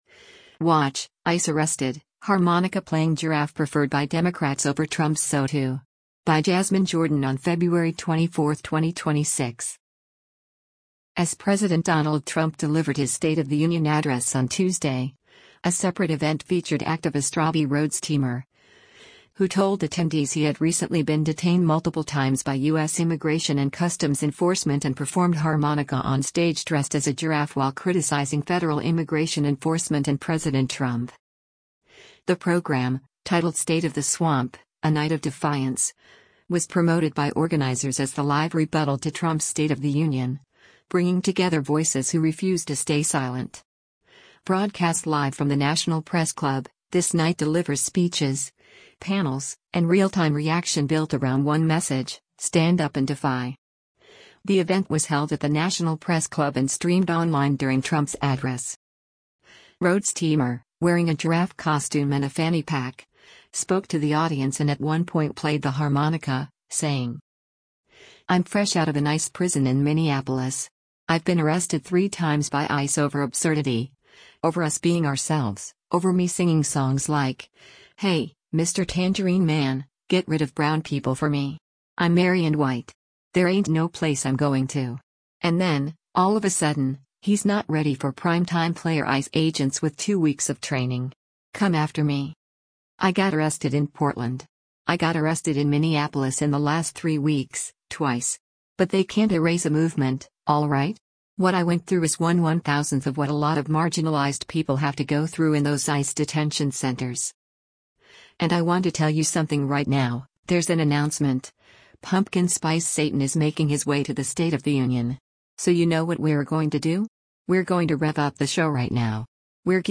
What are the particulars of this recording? The event was held at the National Press Club and streamed online during Trump’s address.